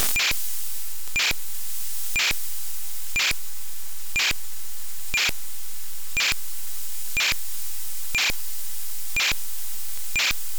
30K wide NFMNarrowband Frequency Modulation demod